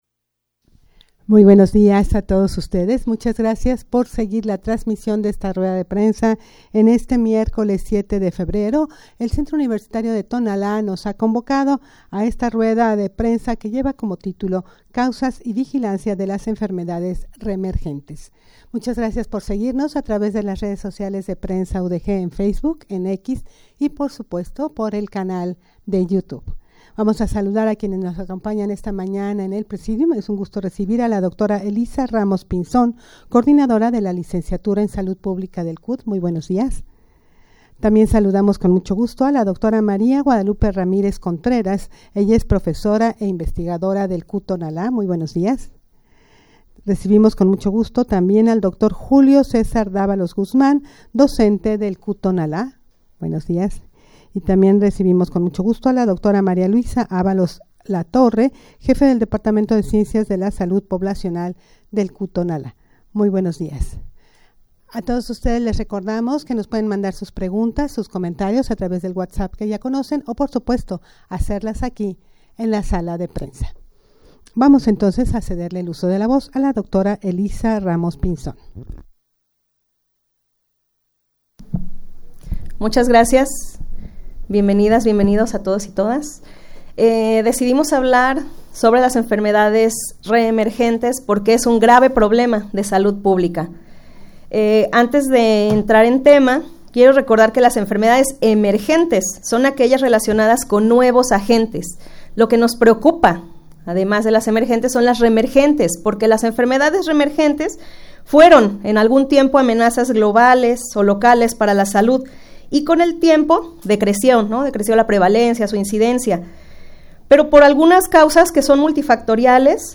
Audio de la Rueda de Prensa
rueda-de-prensa-causas-y-vigilancia-de-las-enfermedades-reemergentes.mp3